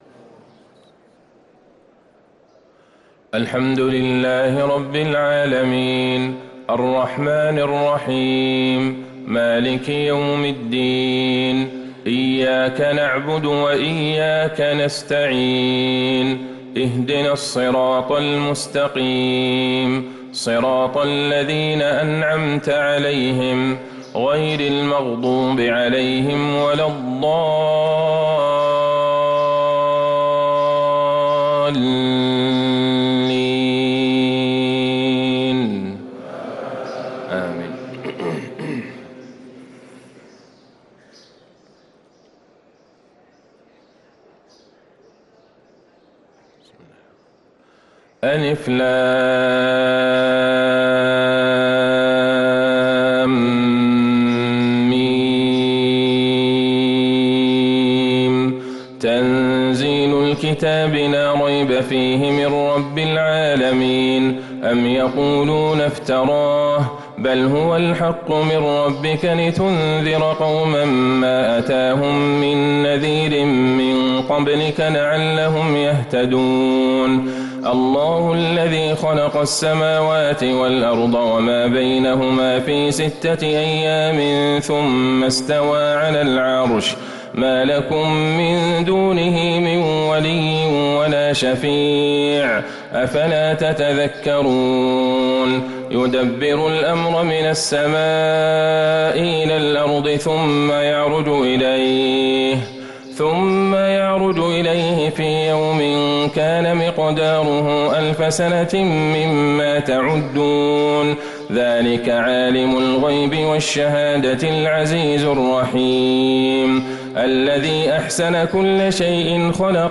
صلاة الفجر للقارئ عبدالله البعيجان 23 جمادي الآخر 1445 هـ
تِلَاوَات الْحَرَمَيْن .